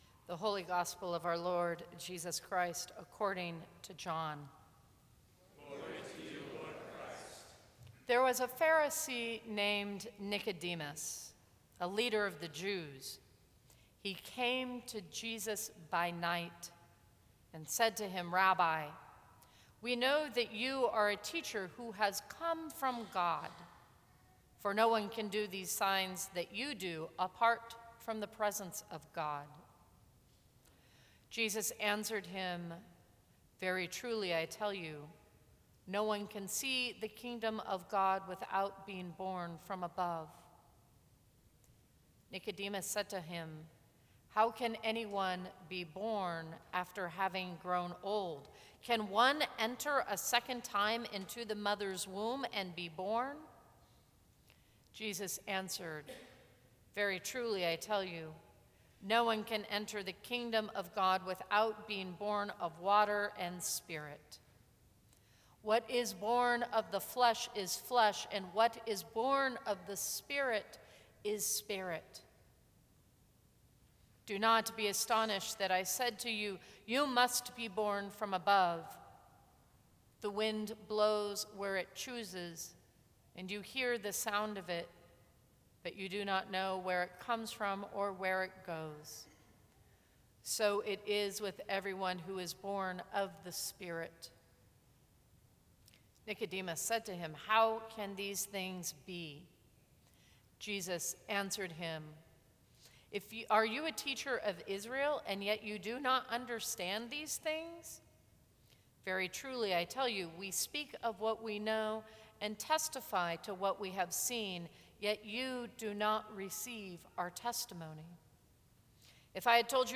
Sermons from St. Cross Episcopal Church March 16, 2014.